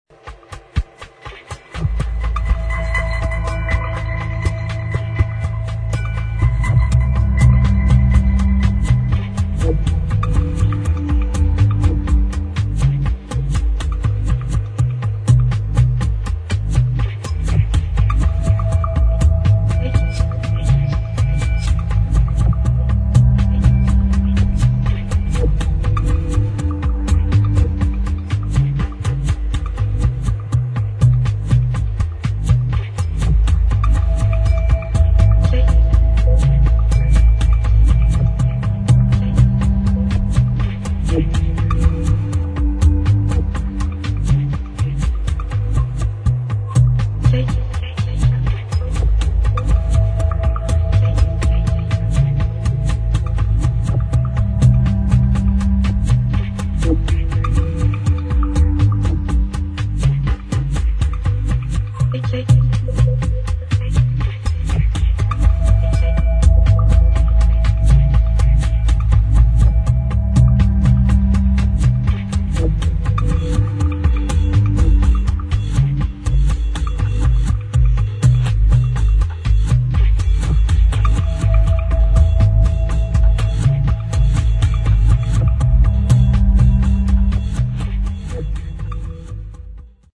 [ ELECTRONIC / EXPERIMENTAL / AMBIENT ]